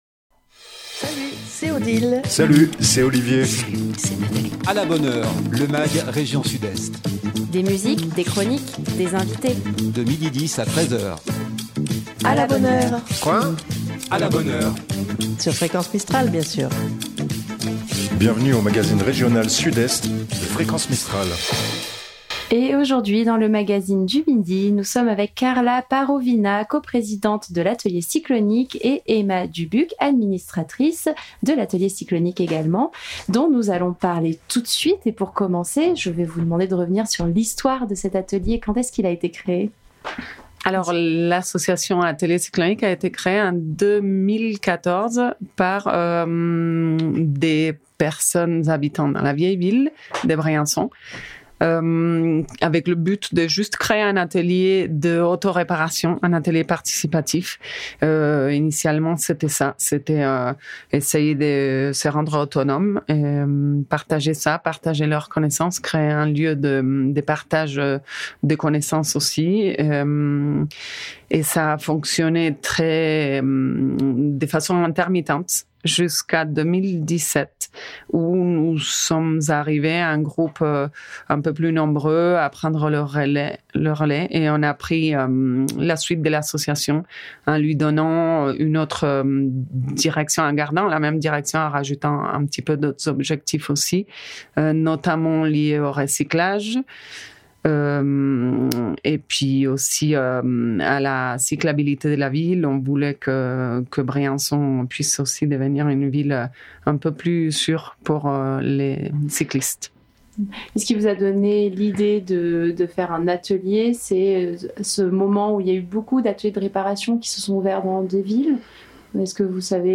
Chaque jour, une antenne différente présente le magazine.